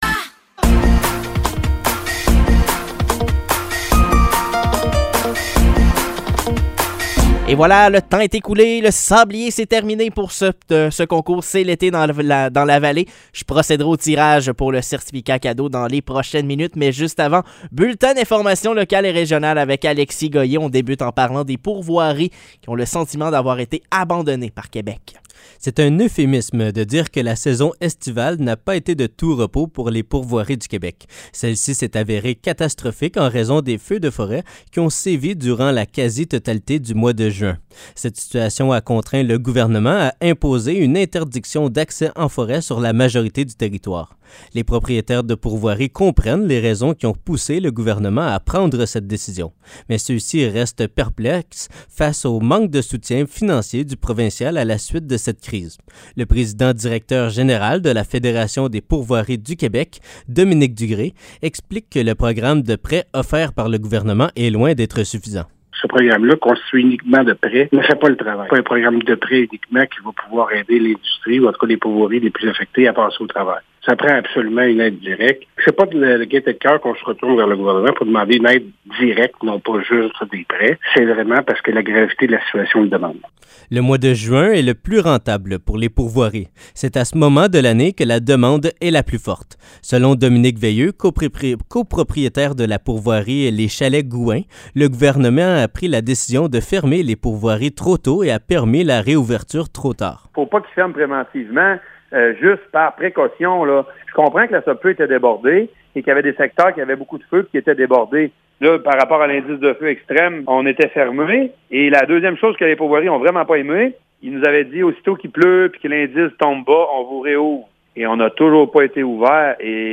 Nouvelles locales - 28 juillet 2023 - 10 h